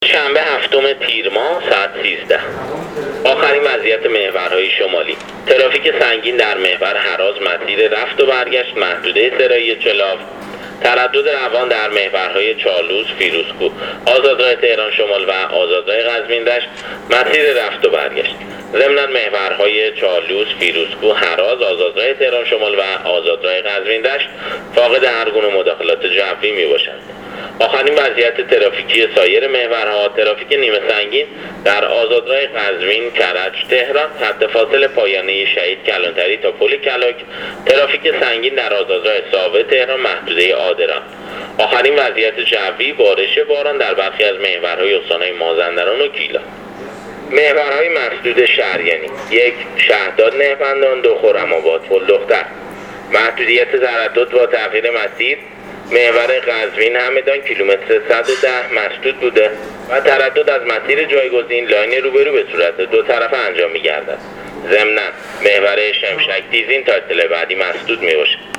گزارش رادیو اینترنتی از وضعیت ترافیکی جاده‌ها تا ساعت ۱۳شنبه ۷ تیر